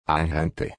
For “arahant”, I split up the syllables, but had difficulty avoiding the Portuguese preference for turning an ending “t” into a “tch”.